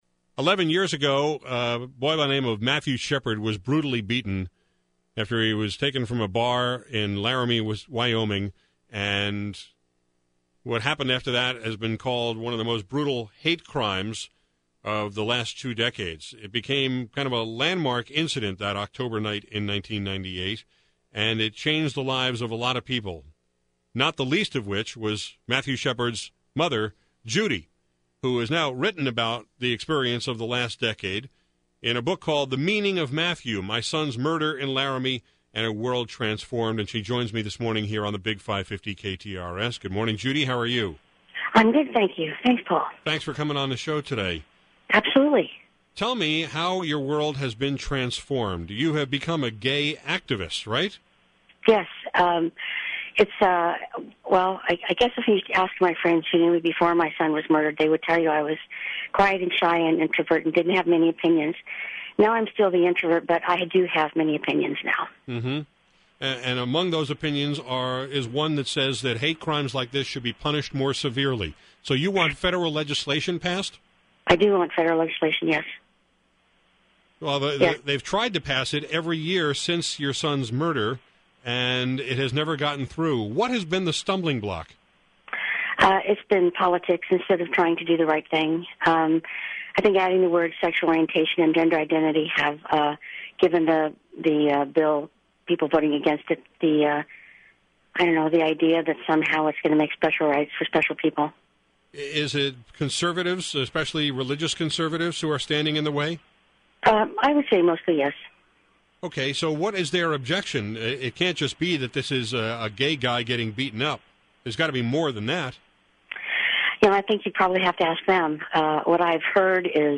On KTRS/St. Louis today, I talked with Judy about why she feels such legislation is necessary, how her son’s murder turned her into a gay rights activist, and how she deals with people in her town who refuse to accept what really happened to Matthew.
As you’ll hear, she started the interview off a little tentatively, but eventually got rolling.